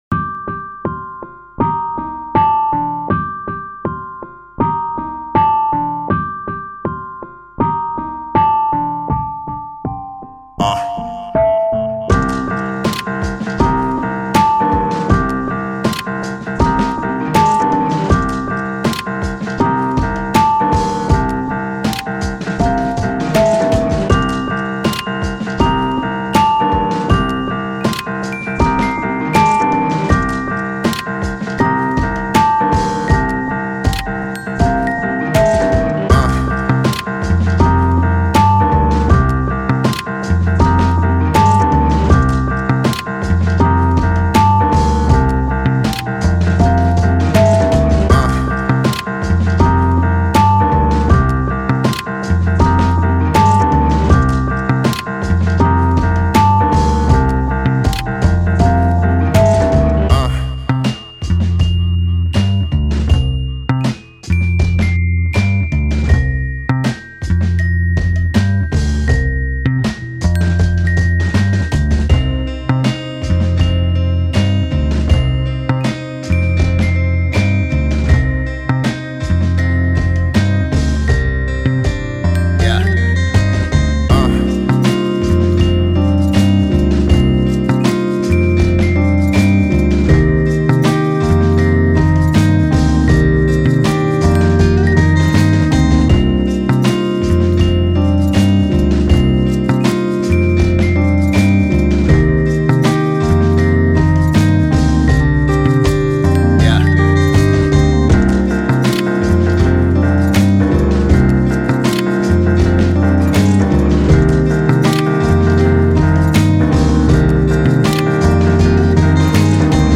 Lofi, Hip Hop, Downtempo, Cheeky